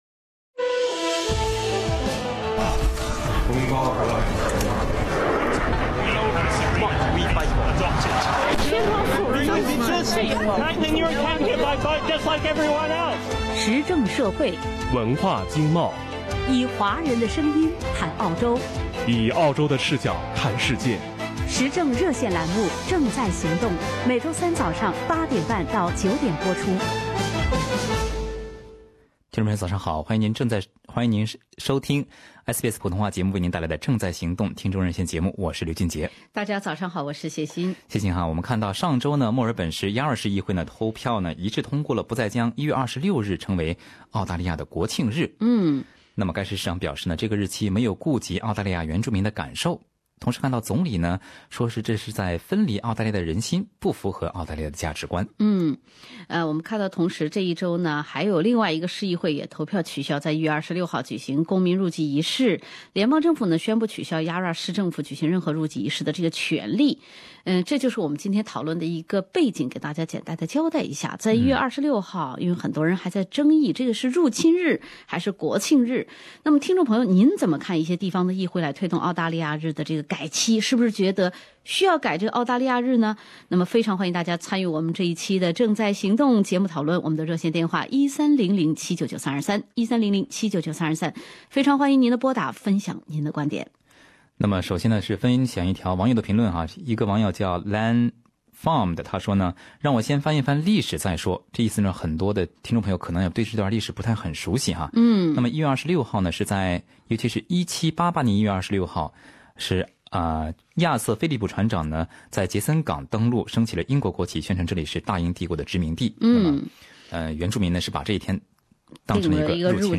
以下为本期热线节目中嘉宾和听众的部分观点。